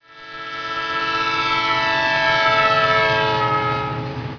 trhorn04.wav